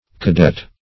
Cadet \Ca*det"\, n. [F. cadet a younger or the youngest son or